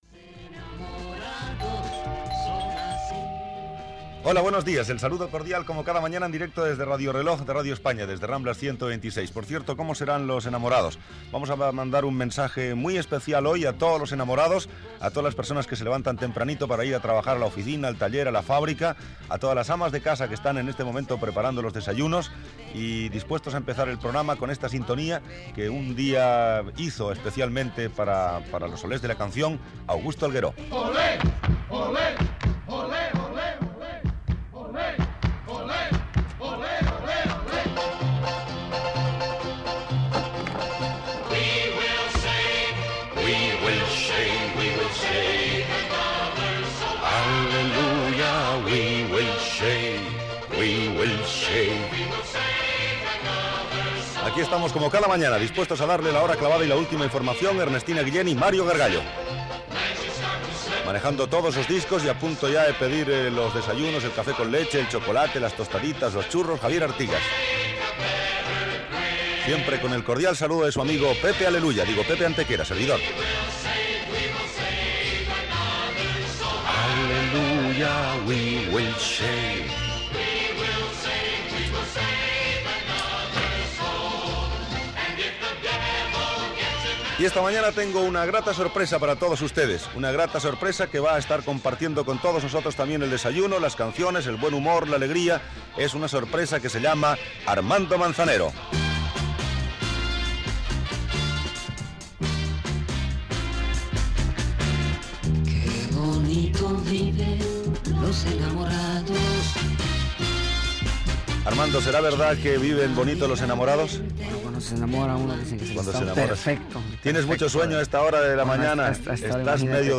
Presentació, equip i entrevista al cantant Armando Manzanero.
Musical